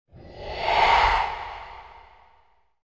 supernatural-breath-sound